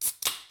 can_open1.ogg